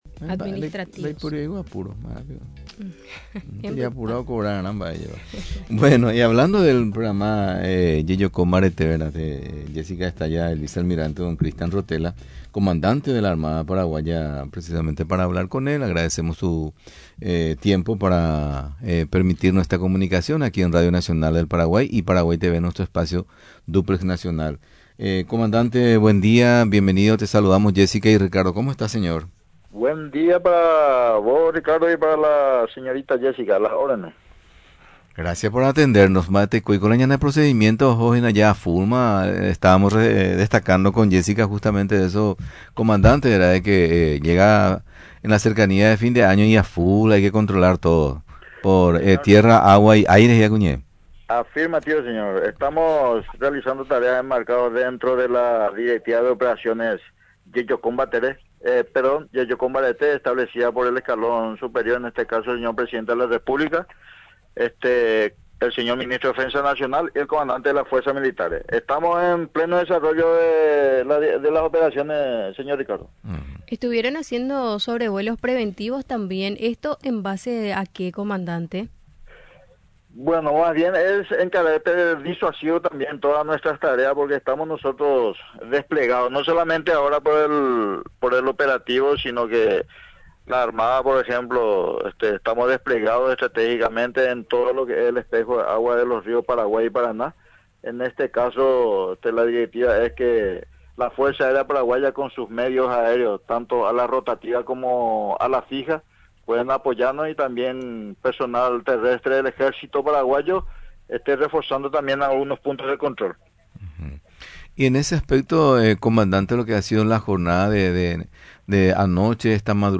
El Vicealmirante Cristhian Rotela, Comandante de la Armada Paraguaya, en conversación con Radio Nacional, destacó que está en plena ejecución la operación «Jejoko Mbarete», a través de una directiva del Poder Ejecutivo y el Ministerio de Defensa. Estos operativos buscan el control riguroso de las fronteras fluviales, terrestres y aéreas del país.